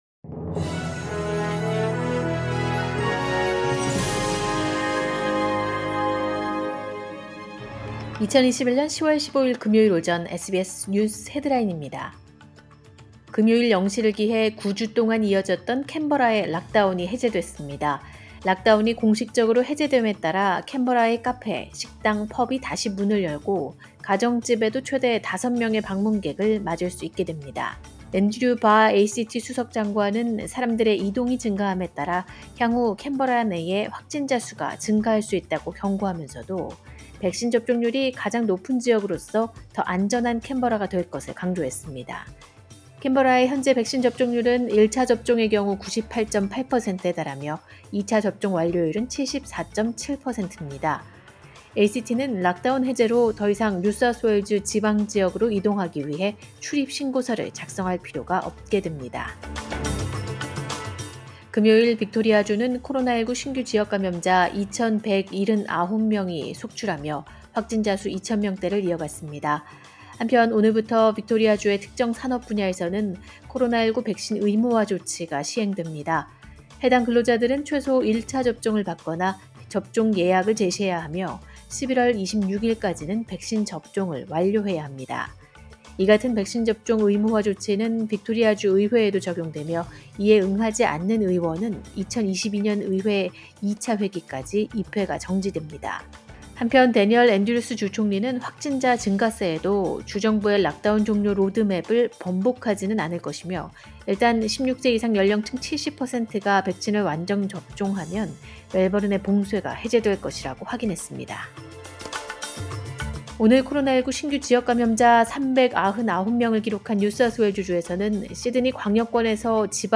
2021년 10월 15일 금요일 오전 SBS 뉴스 헤드라인입니다.